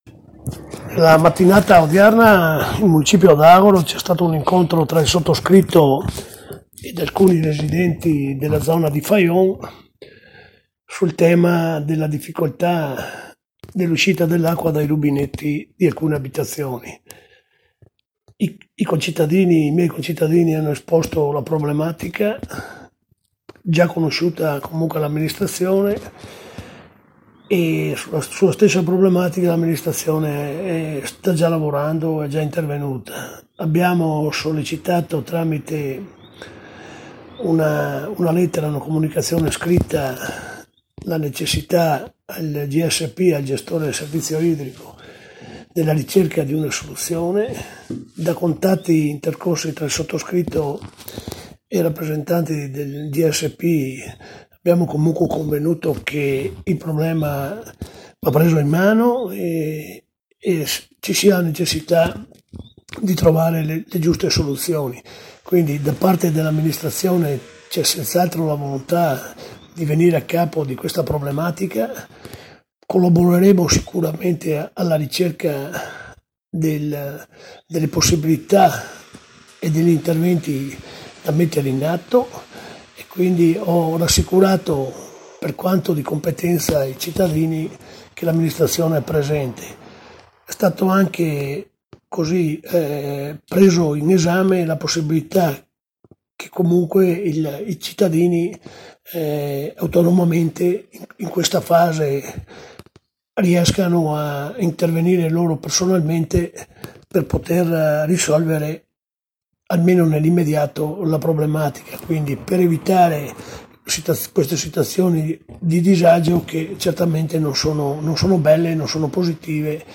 RISPOSTA DEL SINDACO DI AGORDO, SISTO DA ROIT